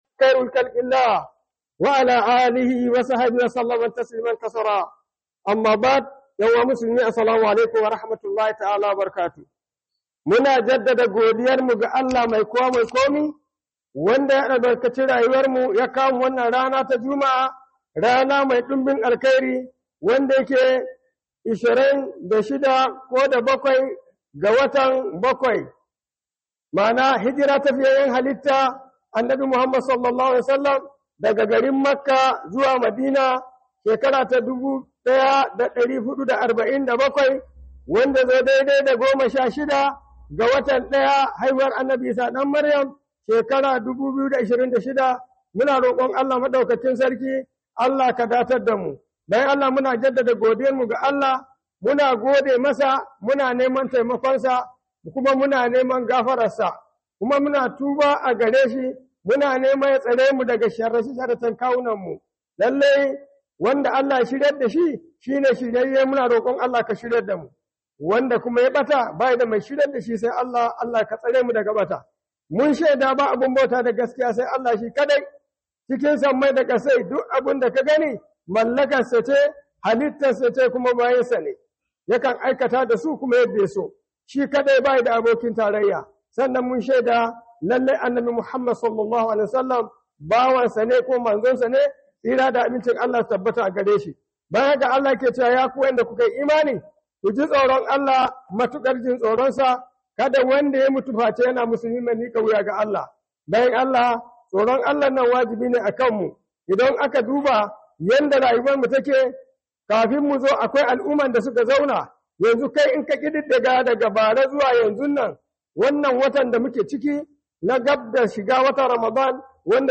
Khudubar Sallar Juma'a by JIBWIS Ningi
Khuduba